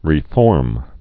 (rē-fôrm)